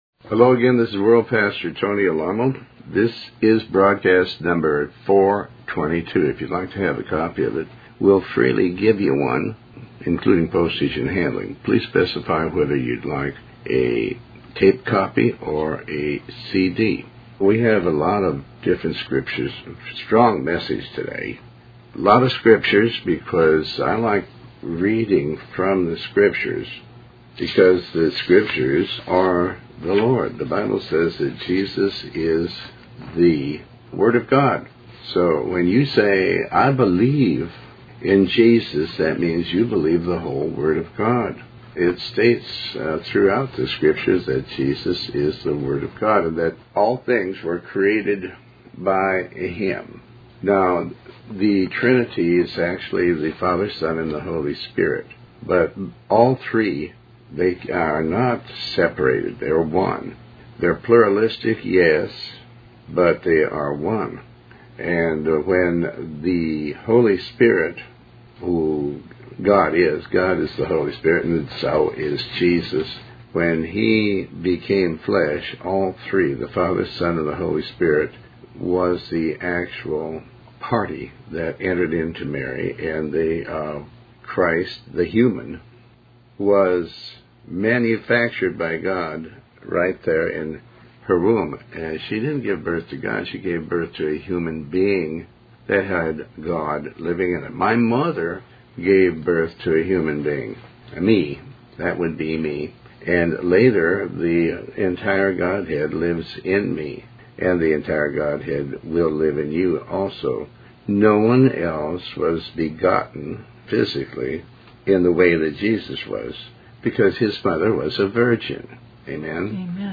Talk Show Episode, Audio Podcast, Tony Alamo and Program 422 on , show guests , about pastor tony alamo,Tony Alamo Christian Ministries,Faith, categorized as Health & Lifestyle,History,Love & Relationships,Philosophy,Psychology,Christianity,Inspirational,Motivational,Society and Culture